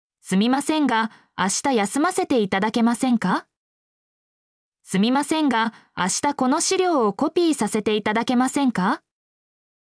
mp3-output-ttsfreedotcom-5_o1Vt8EAu.mp3